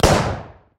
Звуки револьвера
Звук пострілу з револьвера на стрільбищі